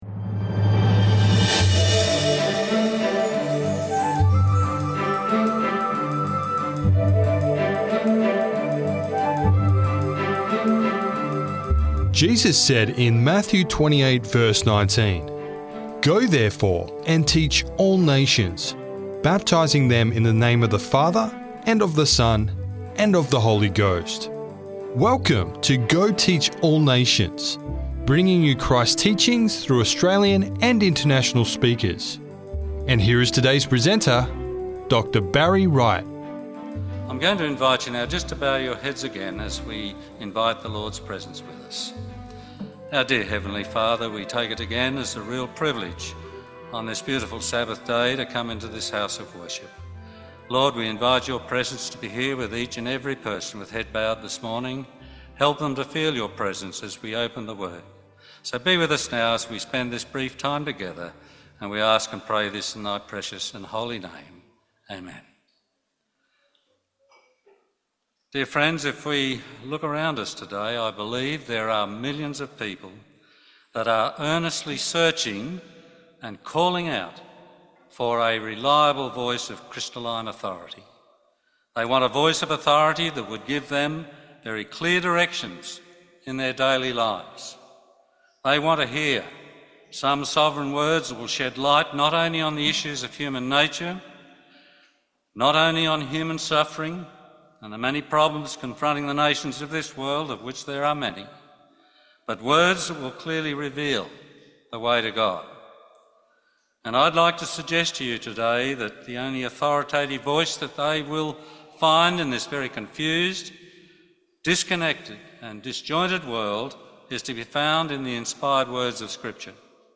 Unveiling the Divinity of Genesis: A Timeless Message - Sermon Audio 2548